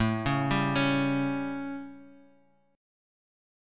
A7 Chord